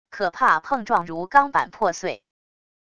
可怕碰撞如钢板破碎wav音频